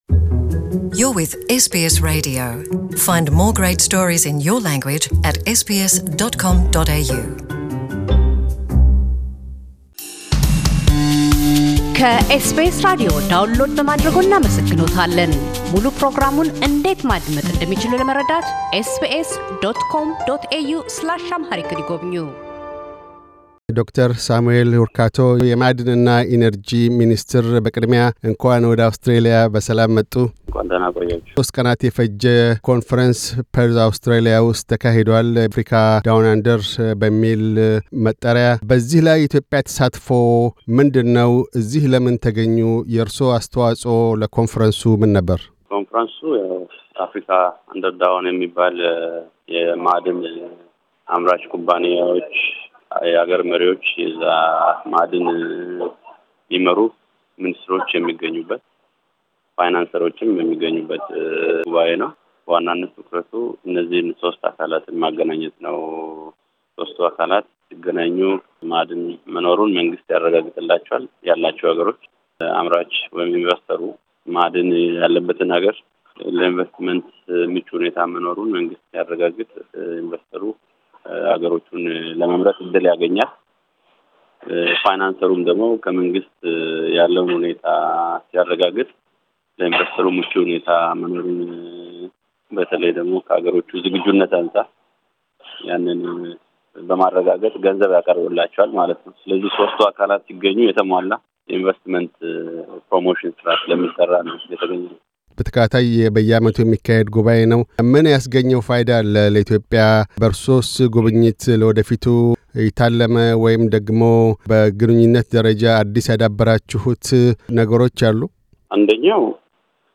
ዶ/ር ሳሙኤል ሁርካቶ፤ የማዕድንና ነዳጅ ሚኒስትር በፐርዝ - አውስትራሊያ ከሴፕቴምበር 4-6, 2019 ስለተካሄደው “Africa Down Under” ኮንፈረንስ ፋይዳዎች፣ የሚኒስቴር መሥሪያ ቤታቸውን ዓመታዊ እንቅስቃሴዎችና ውጥኖች አስመልክተው ይናገራሉ።